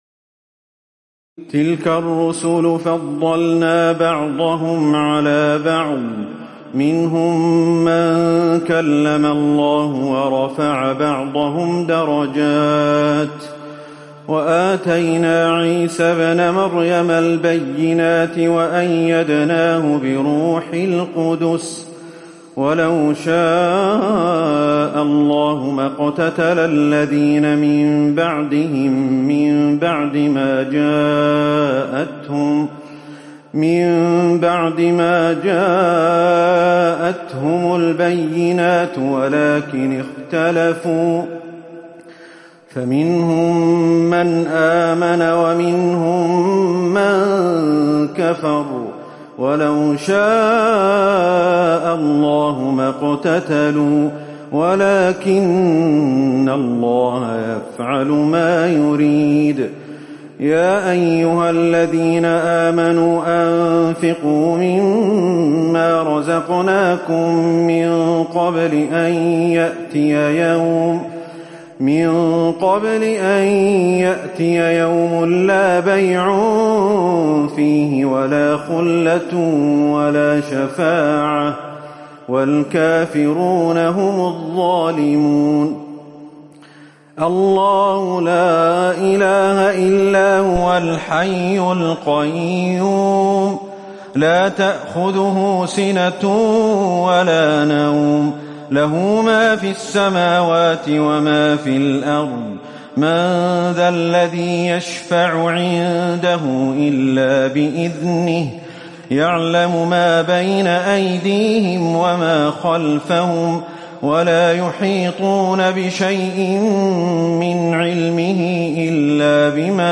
تراويح الليلة الثالثة رمضان 1438هـ من سورتي البقرة (253-286) و آل عمران (1-17) Taraweeh 3st night Ramadan 1438H from Surah Al-Baqara and Surah Aal-i-Imraan > تراويح الحرم النبوي عام 1438 🕌 > التراويح - تلاوات الحرمين